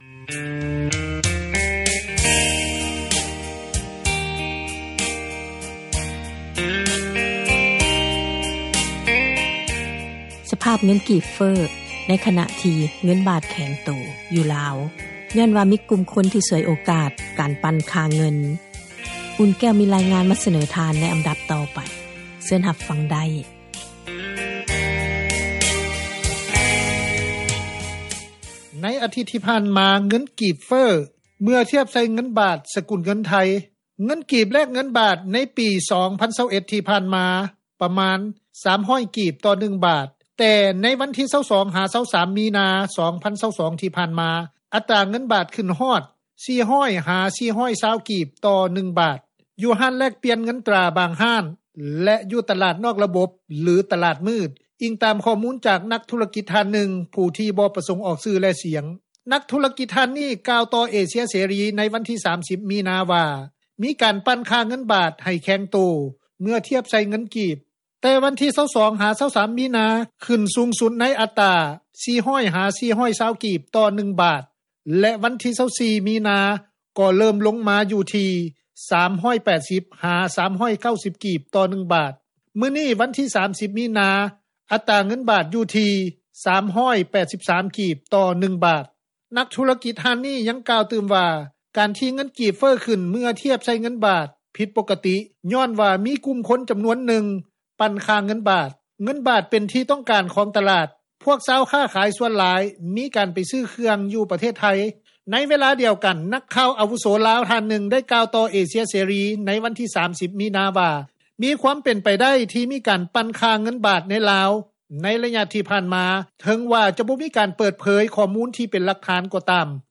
ມີການປັ່ນຄ່າເງິນ ເຮັດໃຫ້ເງິນກີບ ເຟີ້ຫລາຍຂຶ້ນ – ຂ່າວລາວ ວິທຍຸເອເຊັຽເສຣີ ພາສາລາວ